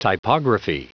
Prononciation du mot typography en anglais (fichier audio)
Prononciation du mot : typography